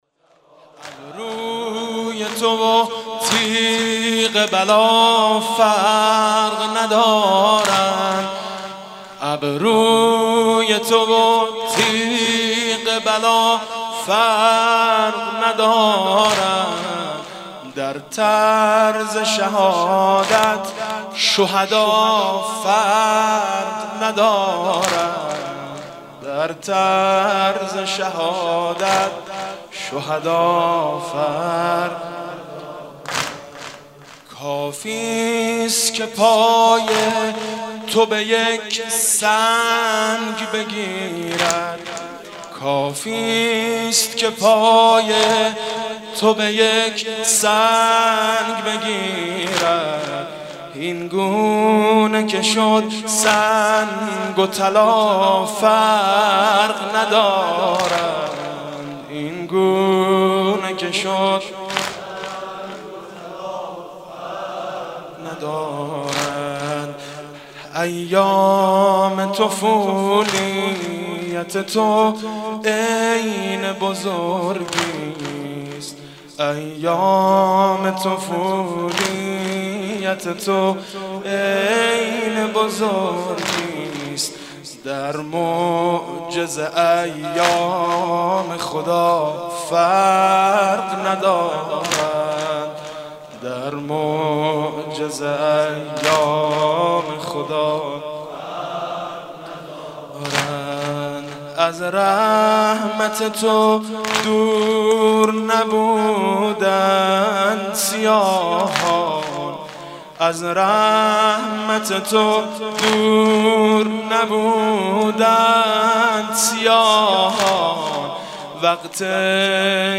«شهادت امام جواد 1390» واحد: ابروی تو و تیغ بلا فرق ندارد